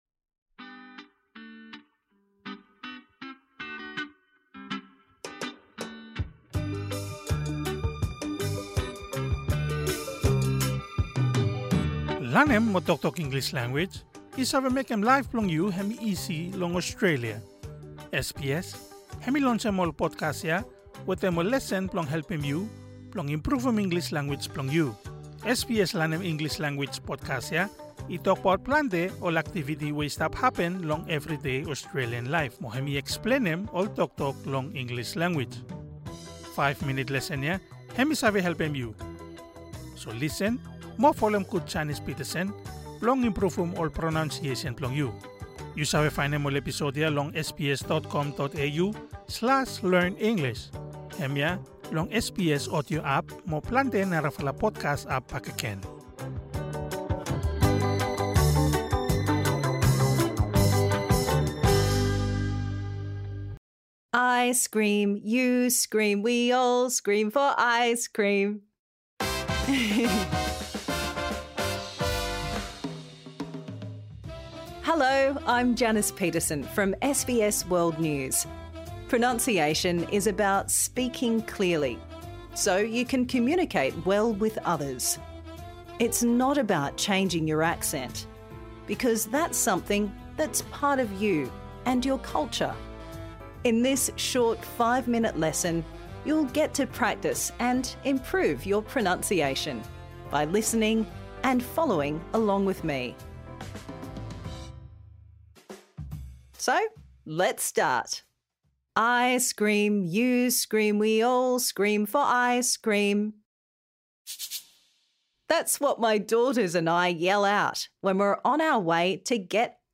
Improve your pronunciation | Season 2
This lesson suits all learners at all levels.